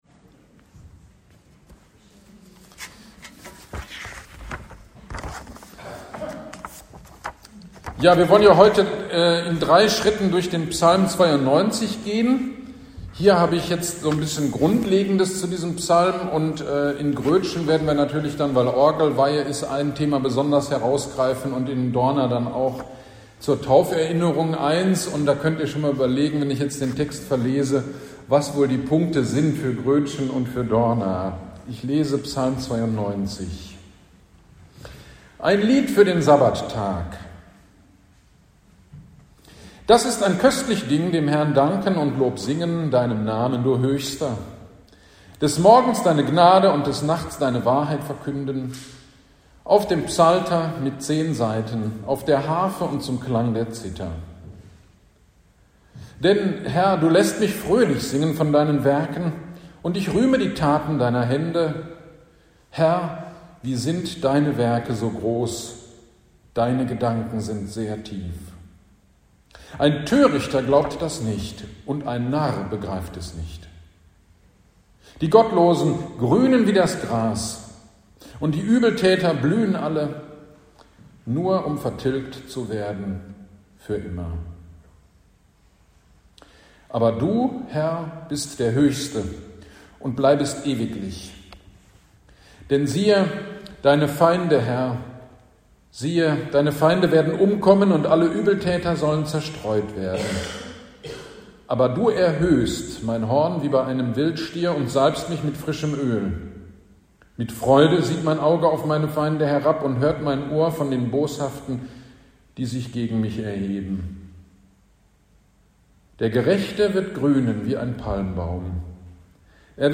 GD am 02.07.23 Predigt zu Psalm 92 - Teil 1 - Kirchgemeinde Pölzig
Predigt-zu-Psalm-92.mp3